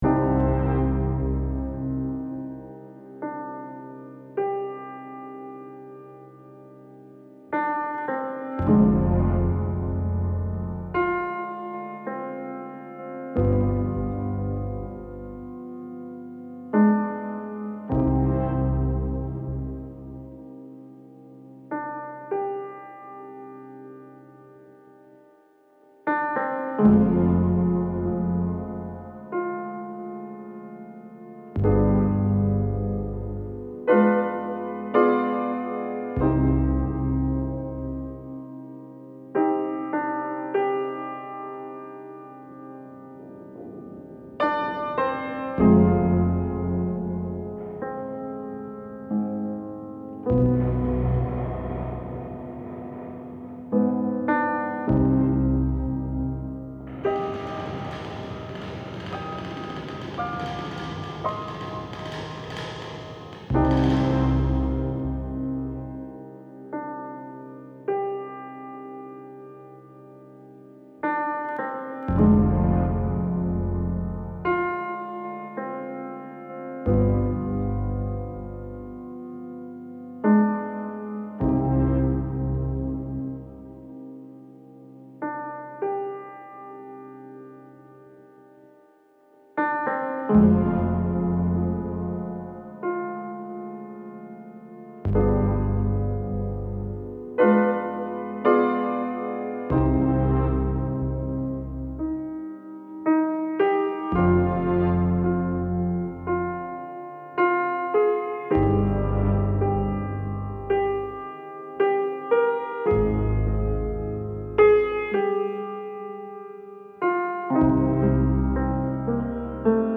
Melancholy and tragic film score with piano theme.